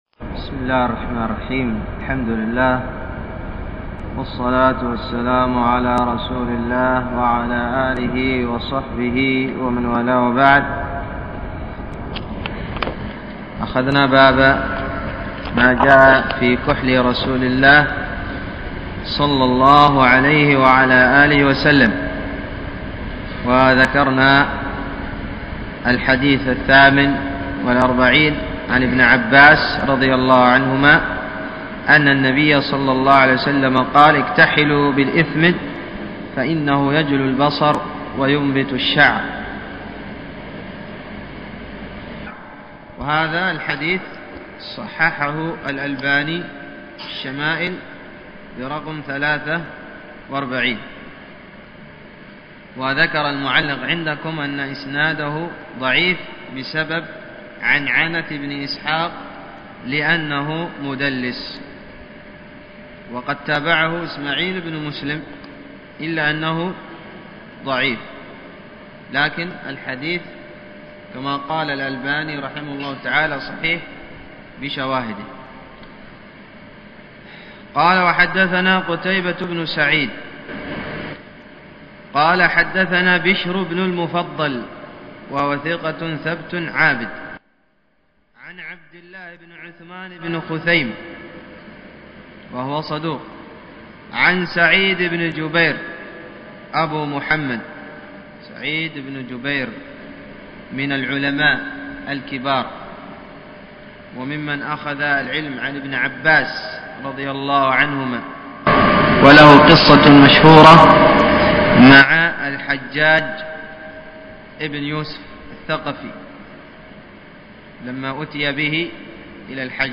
الدرس الثاني عشر